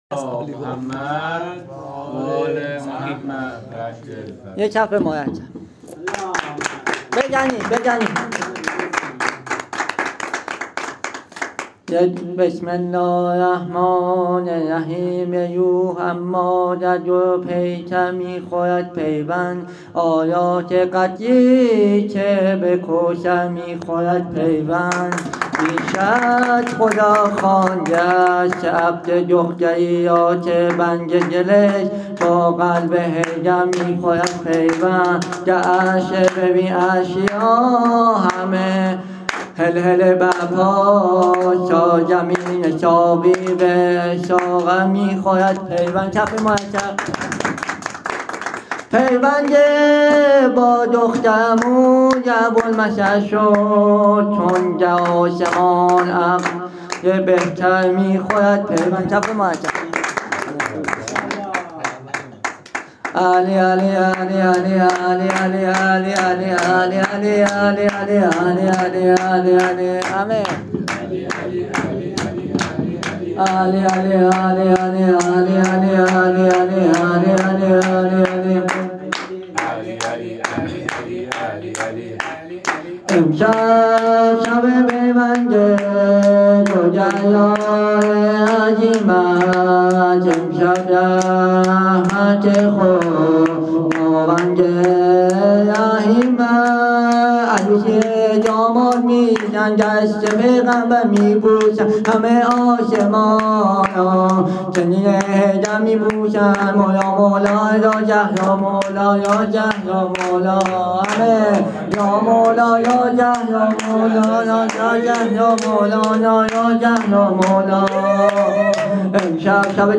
هیت ابافضل العباس امجدیه تهران